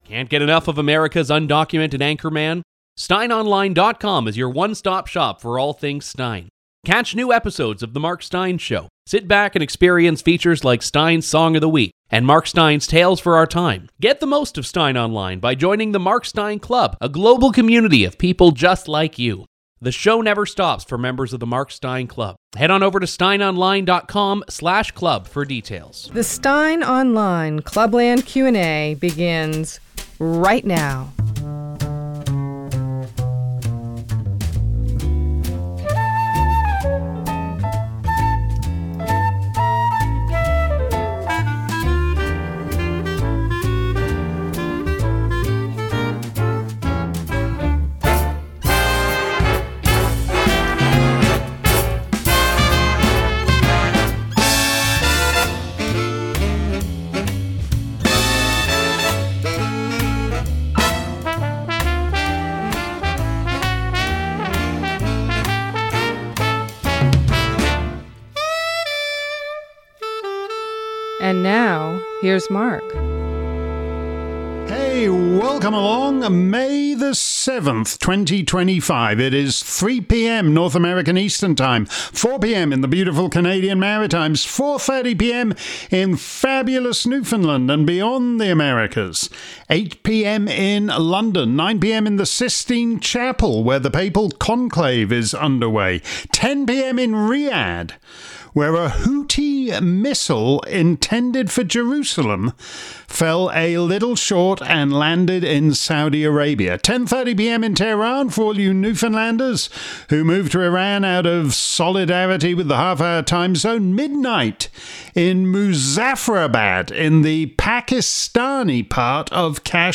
If you missed today's edition of Steyn's Clubland Q&A live around the planet, here's the action replay. This week's show covered a range of topics from the Indian Air Force strike on Pakistan to the catastrophe of "diversity".